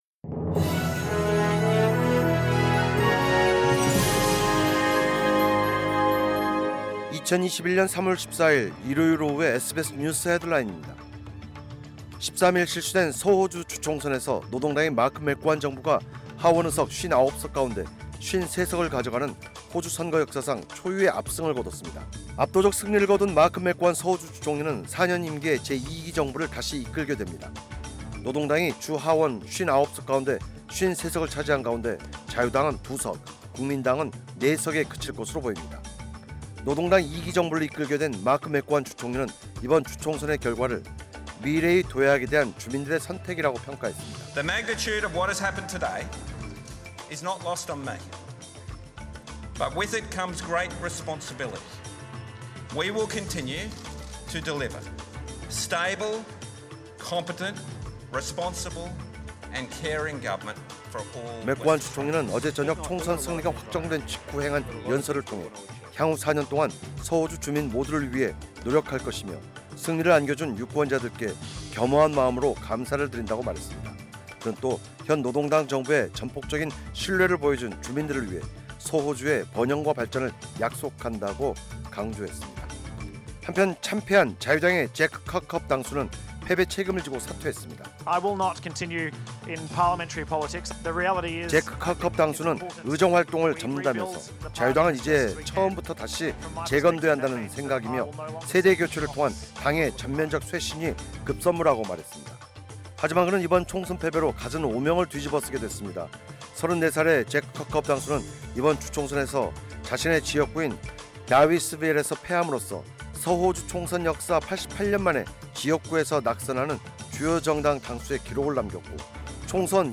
2021년 3월 14일 일요일 오후의 SBS 뉴스 헤드라인입니다.